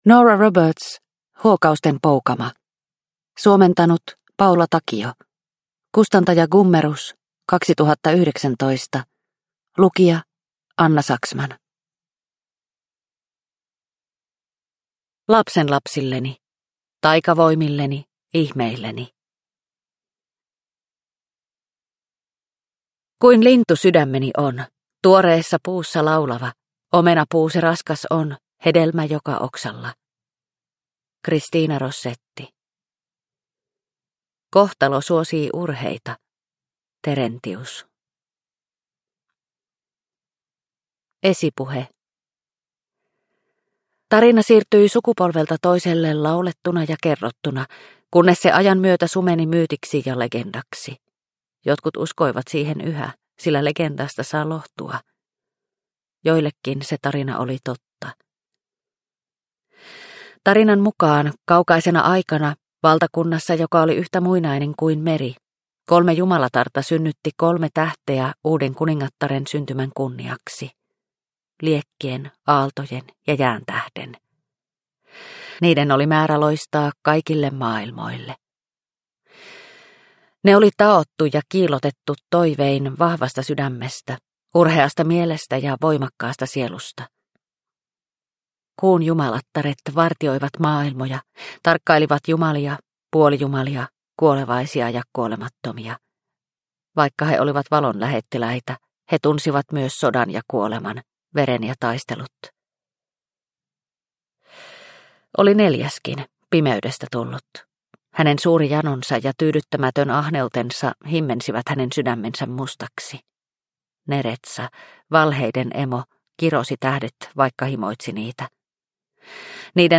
Huokausten poukama – Ljudbok – Laddas ner